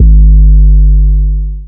DDW3 808 6.wav